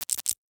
CapersProject/NOTIFICATION_Subtle_04_mono.wav at 2046a2eec1fe2779bcf0a334ae5e8246f47d1a24
NOTIFICATION_Subtle_04_mono.wav